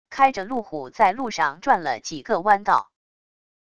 开着路虎在路上转了几个弯道wav音频